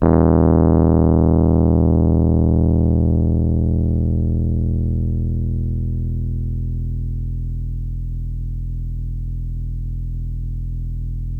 RHODES CL00L.wav